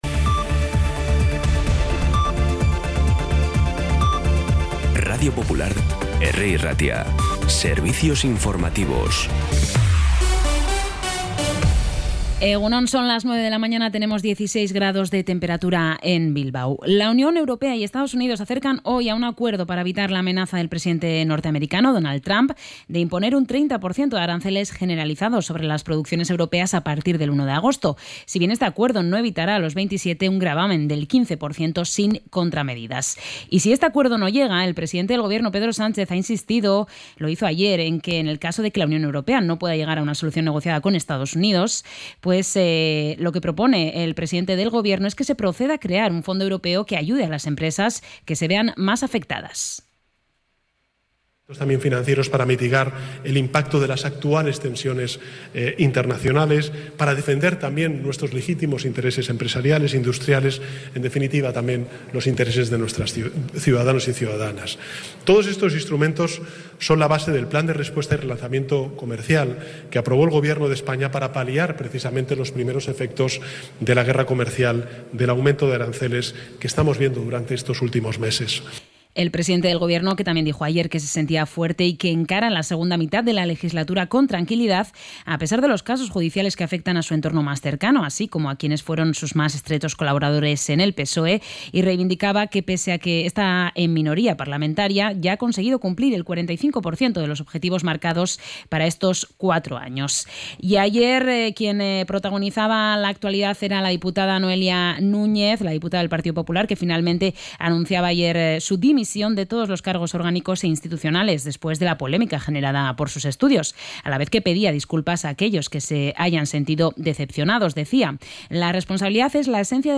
Información y actualidad desde las 9 h de la mañana